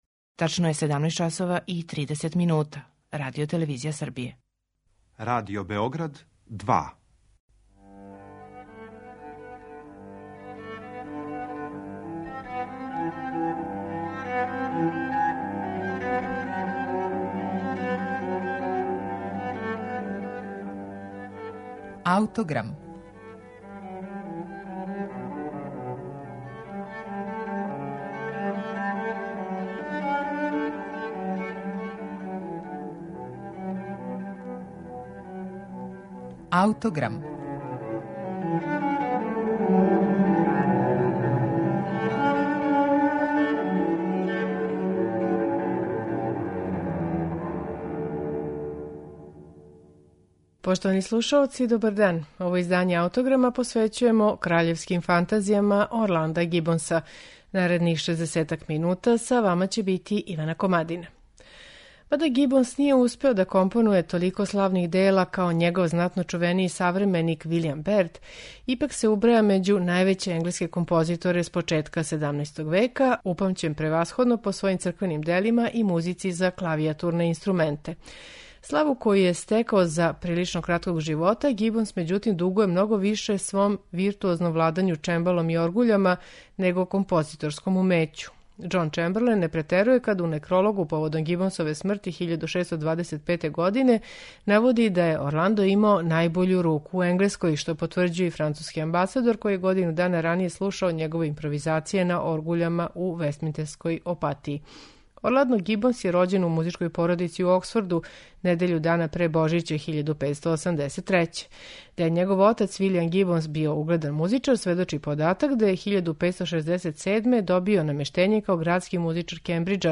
Данашњи Аутограм смо посветили Гибонсовим "Краљевским фантазијама", композицијама писаним за ансамбле од два до шест извођача, делима у којима је Гибонсова композиторска машта нашла подручје на којем ће се најслободније изразити. Тринаест Гибонсових фантазија слушаћете у тумачењу које су на оригиналним инструментима 16. и 17. века остварили
виоле
алт и тенор виола
позитив оргуље